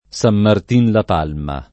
Sam mart&n la p#lma] (Tosc.)